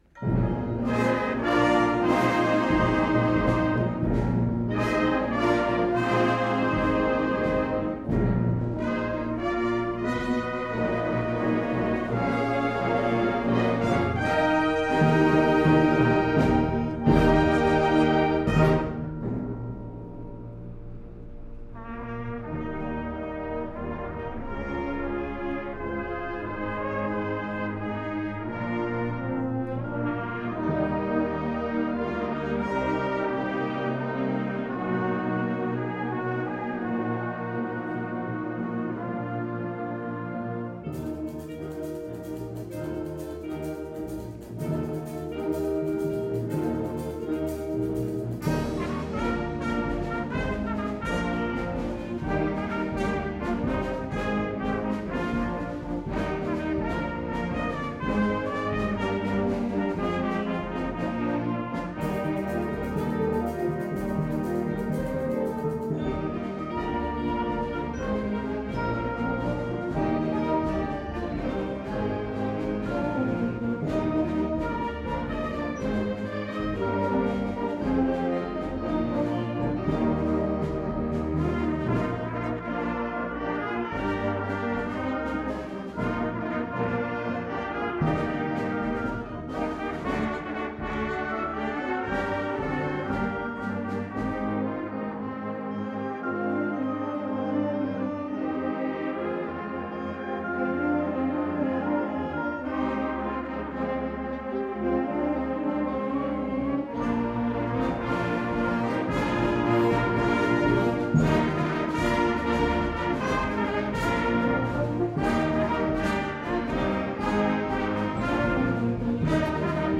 2014 Summer Concert